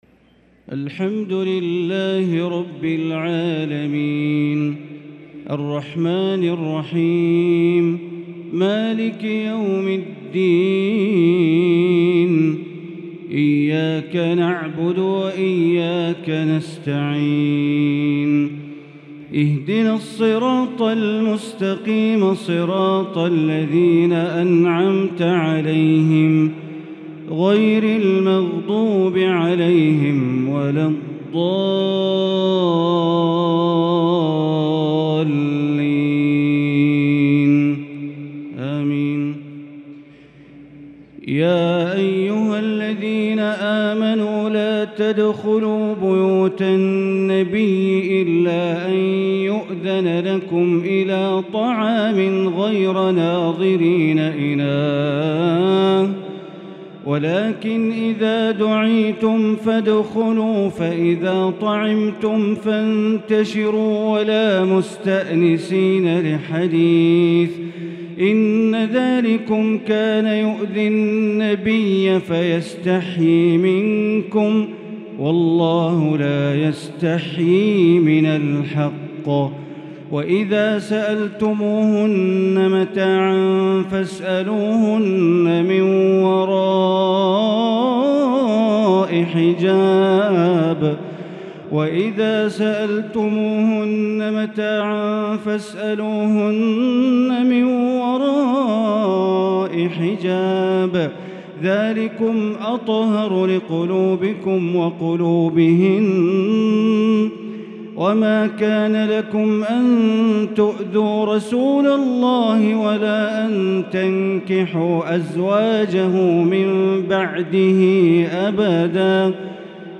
تهجد ليلة 24 رمضان 1443هـ من سورتي الأحزاب و سبأ Tahajjud 24 st night Ramadan 1443H Surah Al-Ahzaab and Saba > تراويح الحرم المكي عام 1443 🕋 > التراويح - تلاوات الحرمين